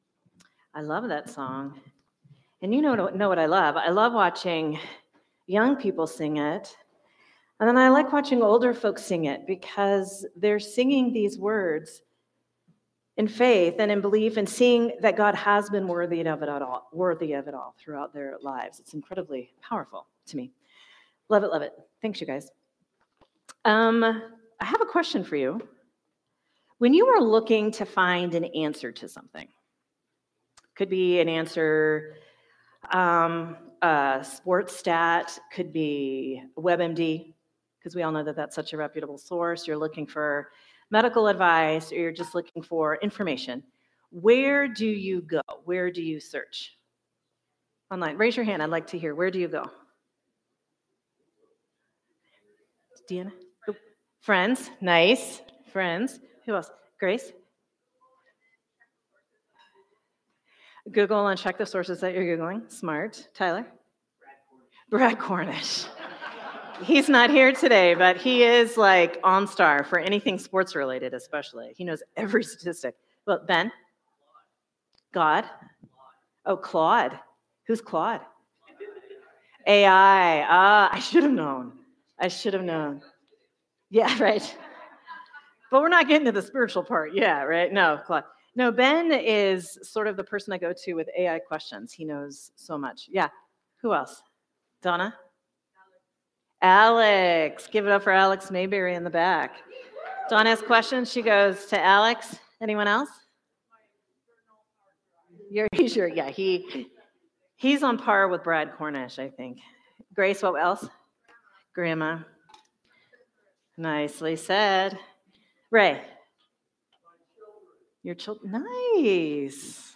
Sermon from Celebration Community Church on August 31, 2025